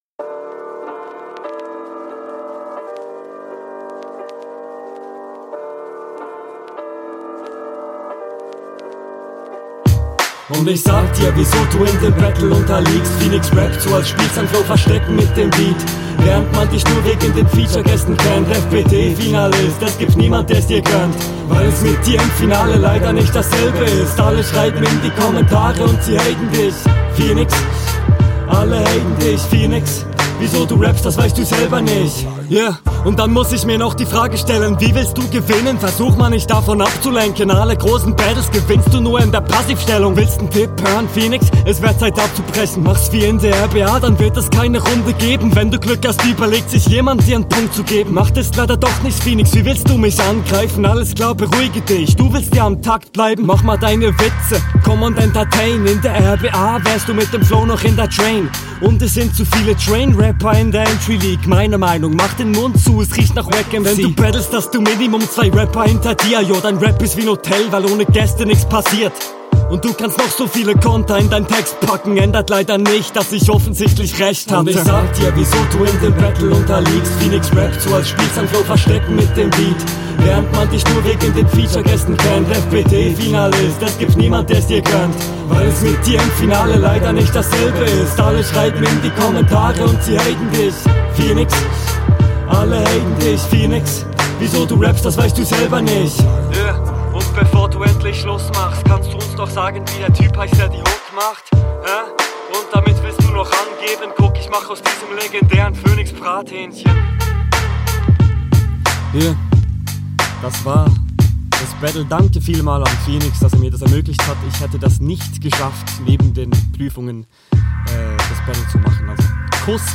Also erstmal, die Runde ist die mit Abstand angenehmste soweit, Hook klingt gut, flowlich sehr …
okey damn, das ist episch! hook sehr cool, beat baut sich gut auf, flowlich sehr …